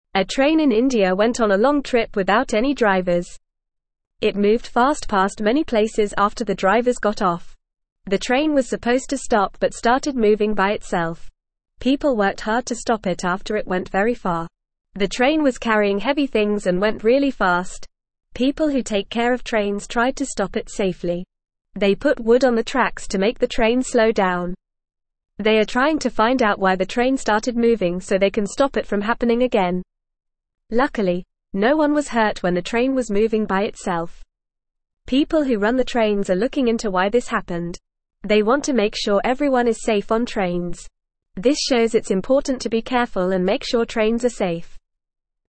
Fast
English-Newsroom-Lower-Intermediate-FAST-Reading-Runaway-Train-in-India-Goes-on-Long-Trip.mp3